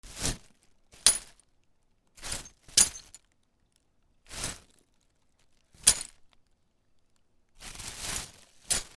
ガラスの欠片の入ったビニール袋を持ち上げる 置く
/ H｜バトル・武器・破壊 / H-45 ｜ガラス
『ザッ カシャ』